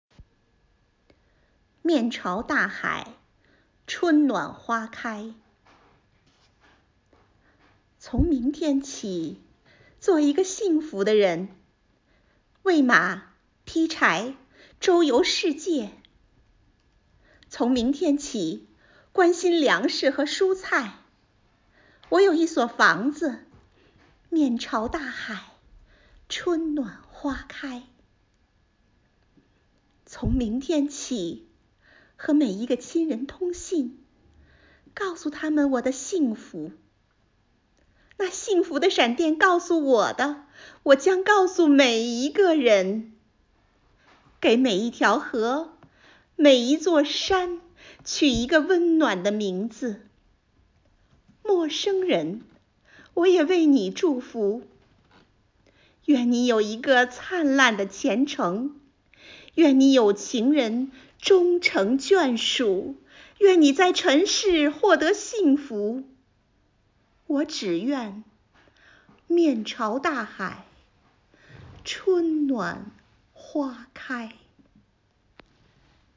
为赞美“逆行英雄”甘于奉献、大爱无疆的崇高精神，女教师们精心创作或挑选朗诵诗歌作品，用饱含深情的声音歌颂和致敬奋斗在一线的抗“疫”英雄们，讴歌了中华民族的伟大和坚韧，表达了对祖国和武汉的美好祝福，热切盼望疫情过后的春暖花开。
附件：一、抗“疫”事迹诗朗诵选登